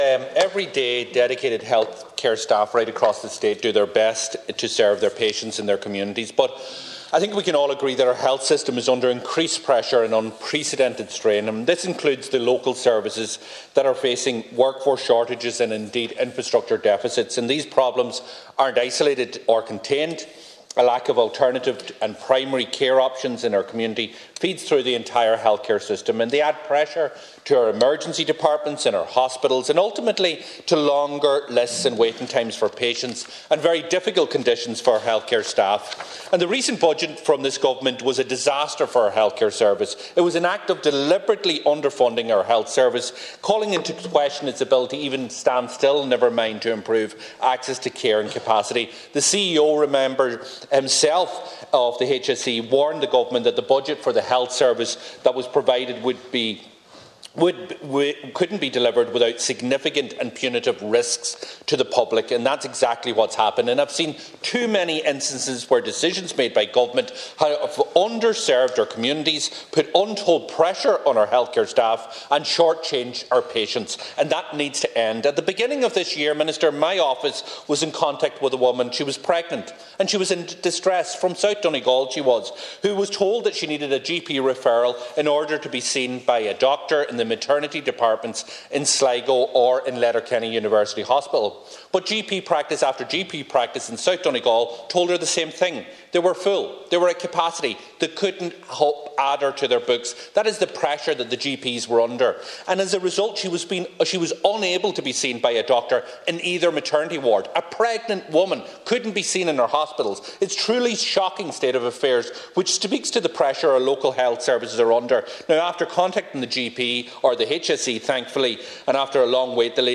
Deputy Doherty says it’s an issue that never should have happened: